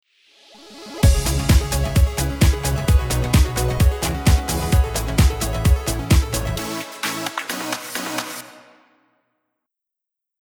szignálzenéje